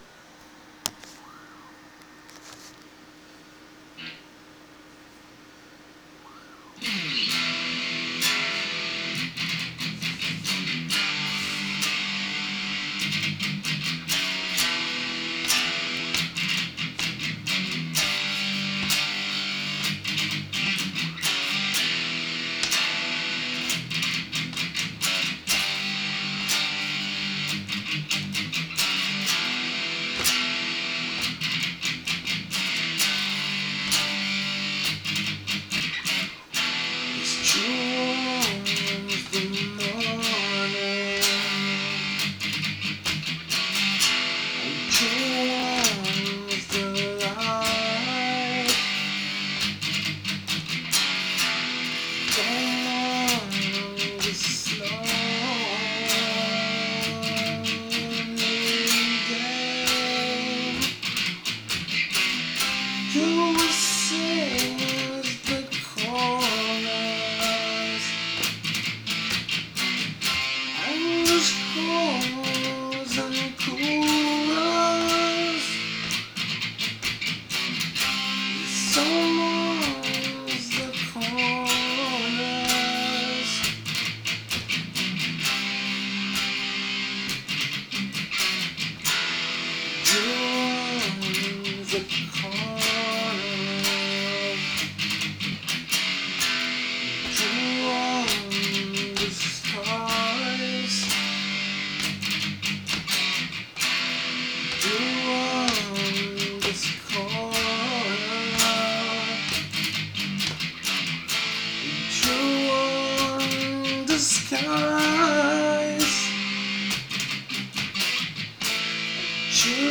Grunge alternative pop rock?? take 1 did not convince me direct link (wav) recording time: 1oct2013 take 2 made up my mind direct link (wav) recording time: 1oct2013